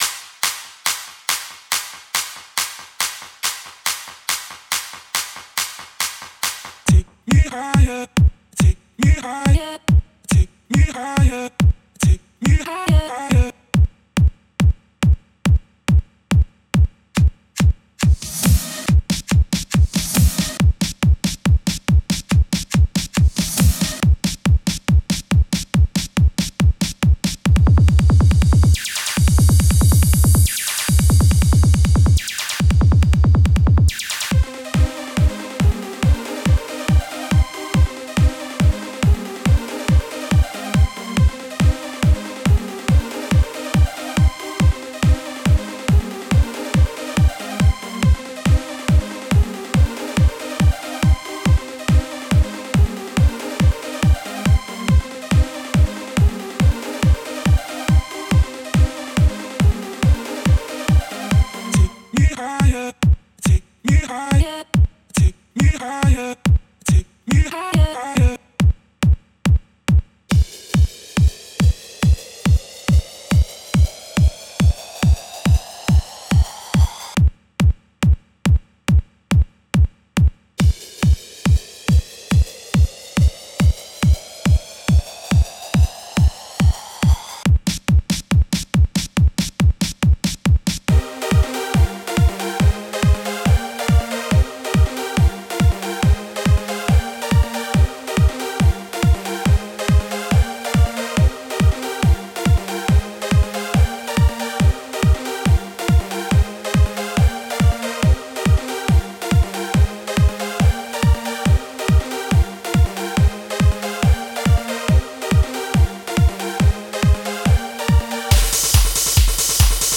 BGM
EDMアップテンポ激しい